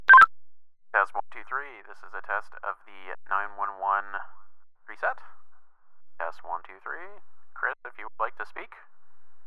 Emergency Call Example
This voice effect utilizes EQ customizations for the best possible phone call effect.
emergency_example_1.mp3